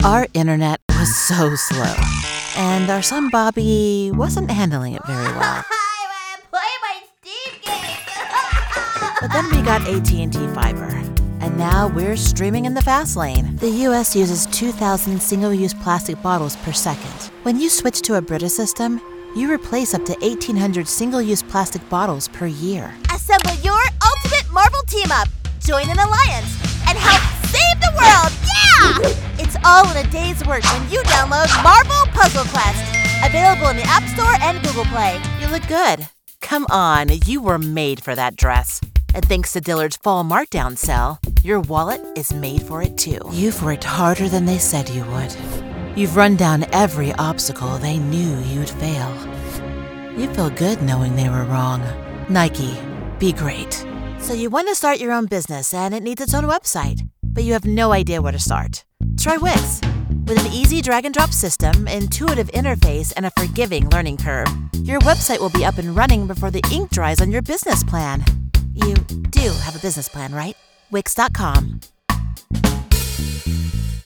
COMMERCIAL 💸